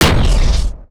weapon_shotgun_001.wav